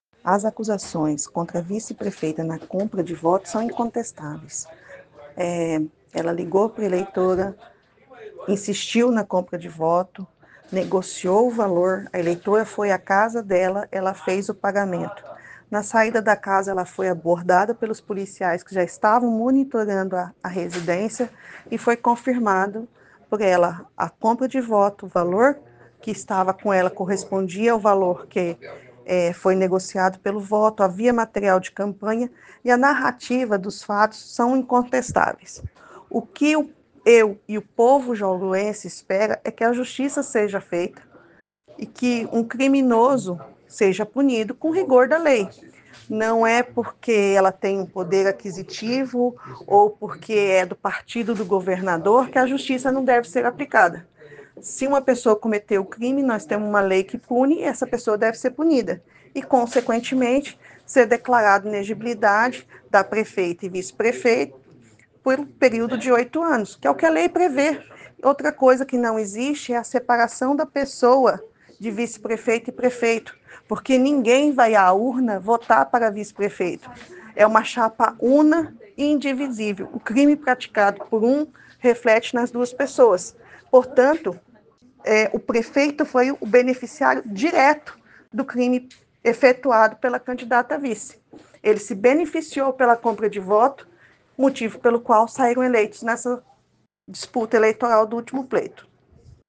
audio-2-advogada.mp3